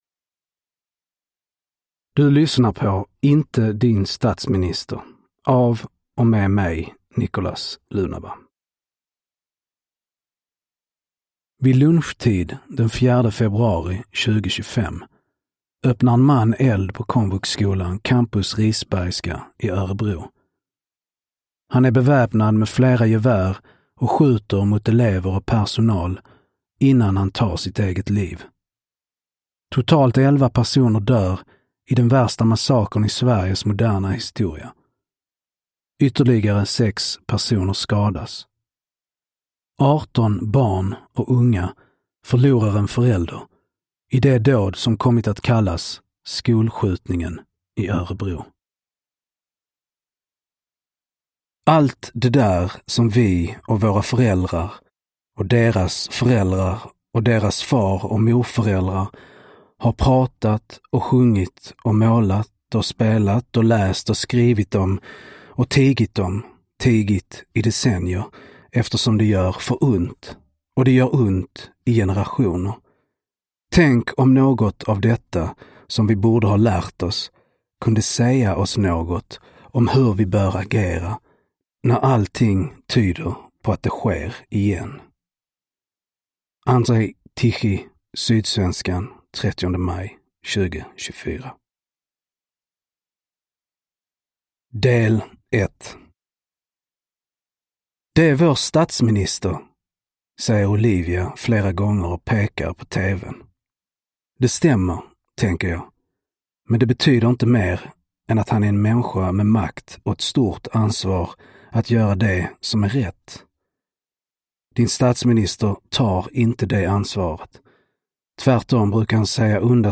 Inte din statsminister (ljudbok